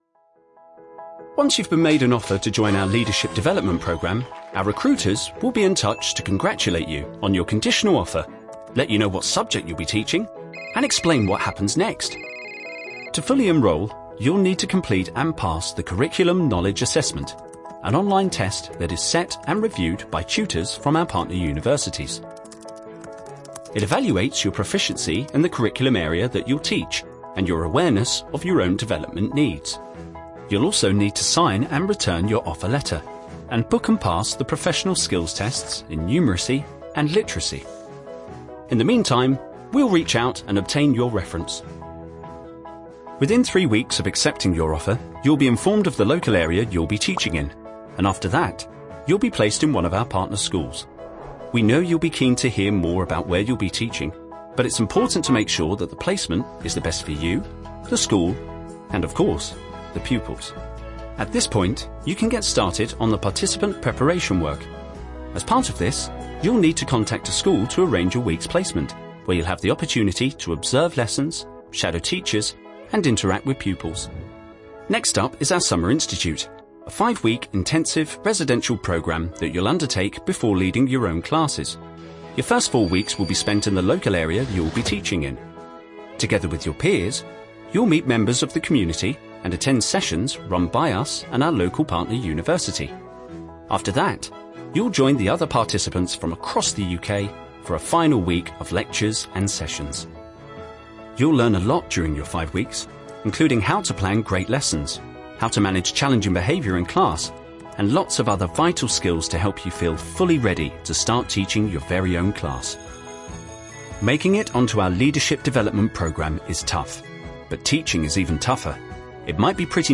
Vidéos explicatives
Cabine d'enregistrement - Studio climatisé sur mesure Session Booth
Microphone - Neumann TLM 103
Anglais (britannique)